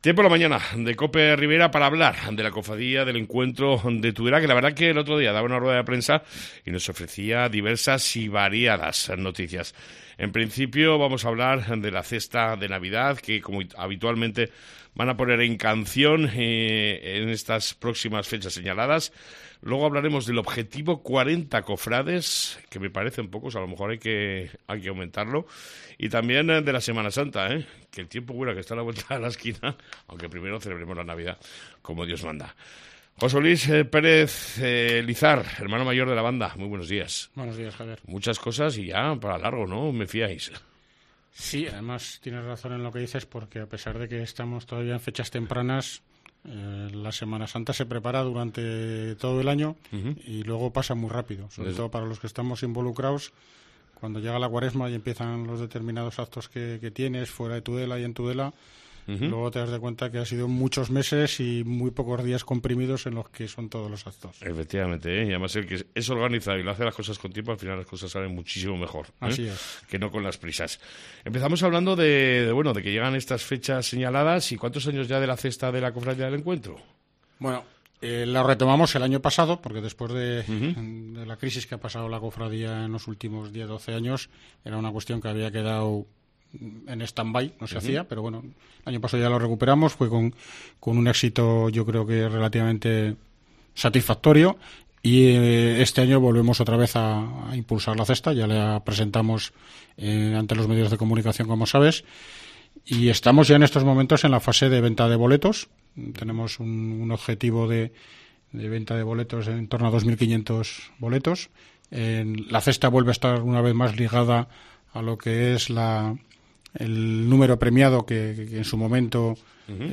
Entrevista con la Cofradia el Encuentro de Tudela